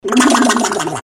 • Качество: 320, Stereo
без слов
смешные